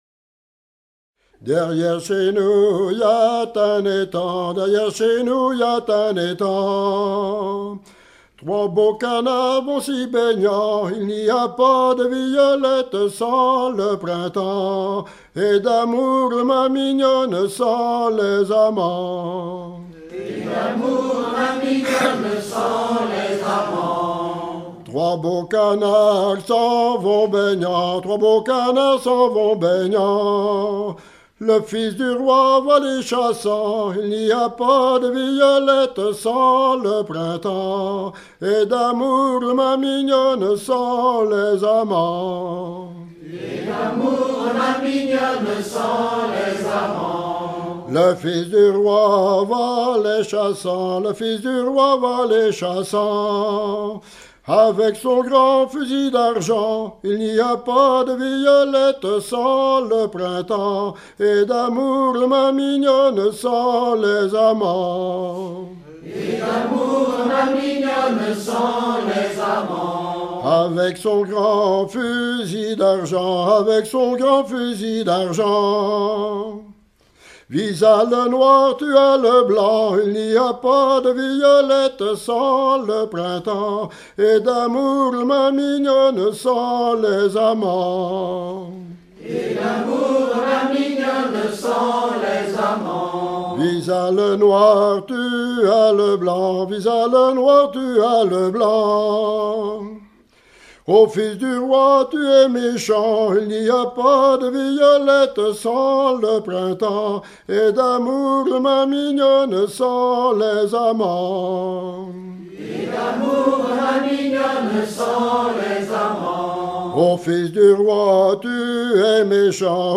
Fonction d'après l'analyste gestuel : à marcher
Genre laisse